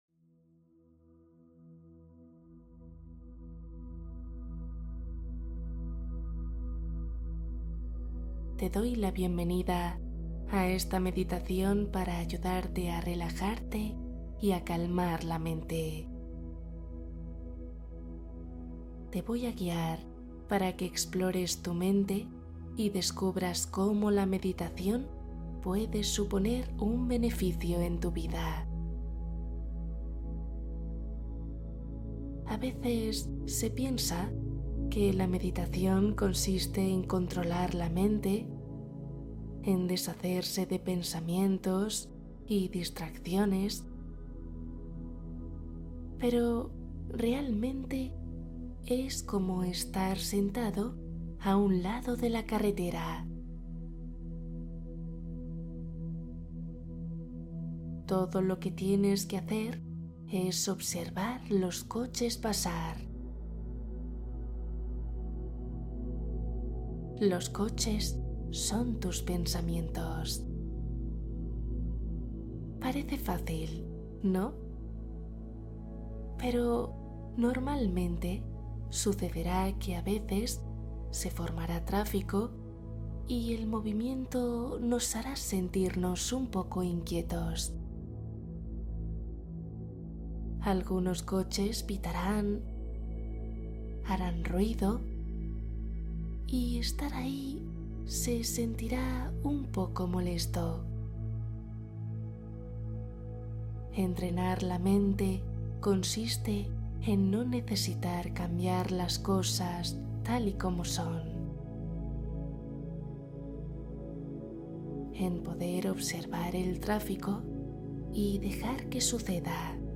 Sueño profundo al instante Meditación para dormir profundamente